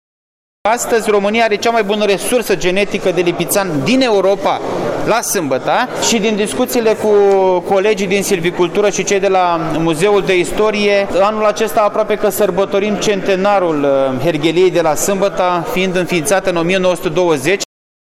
ALEXE-HERGHELIE.mp3